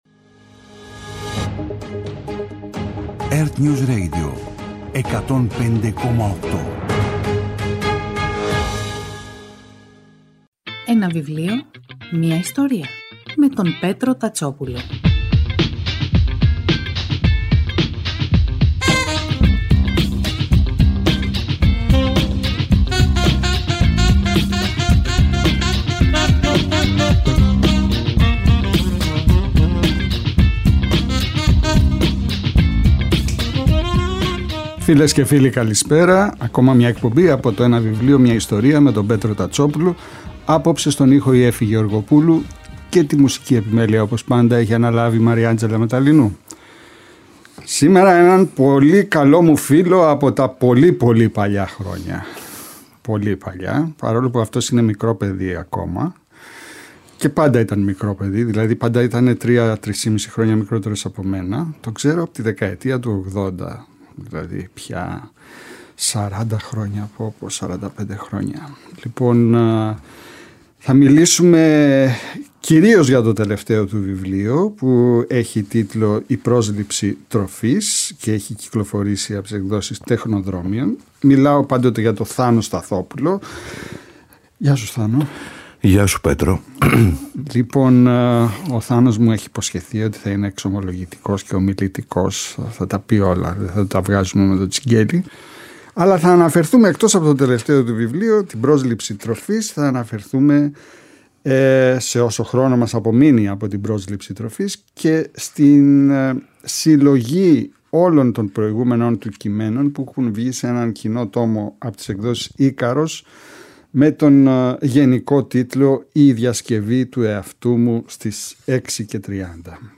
Κάθε Σάββατο και Κυριακή, στις 5 το απόγευμα, ο Πέτρος Τατσόπουλος παρουσιάζει ένα συγγραφικό έργο, με έμφαση στην τρέχουσα εκδοτική παραγωγή, αλλά και παλαιότερες εκδόσεις.